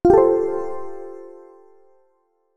alert6.wav